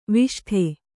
♪ viṣṭhe